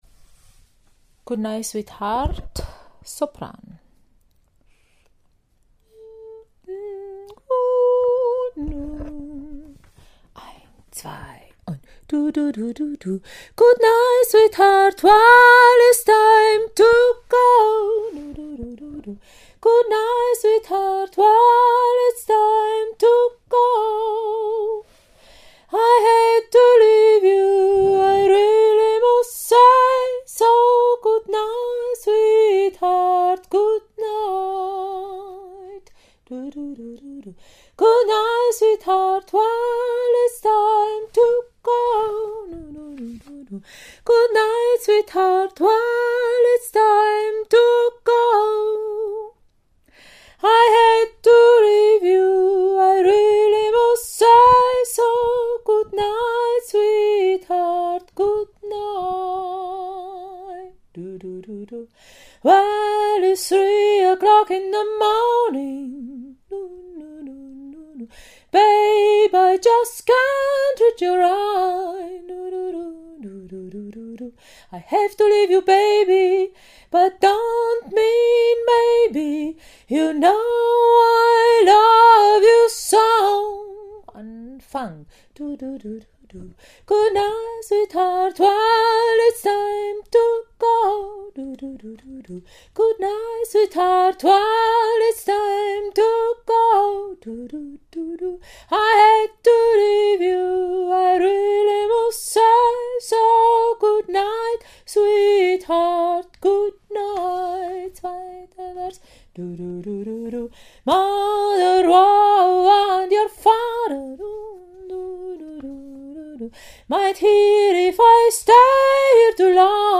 Goodnight Sweetheart – Tenor